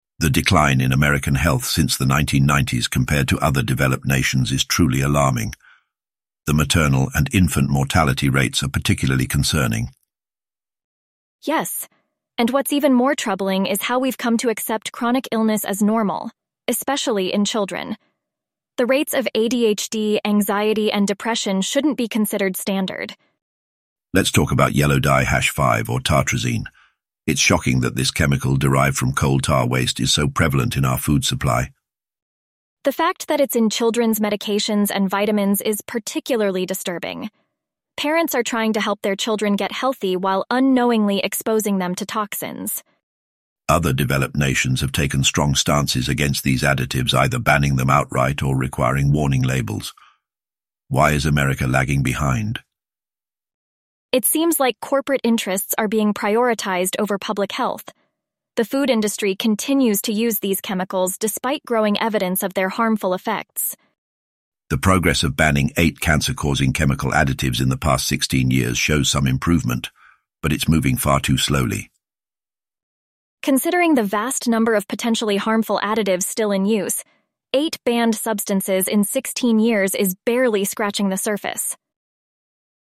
AI podcast summary from a youtube video using Anthropic or XAI and Elevenlabs voices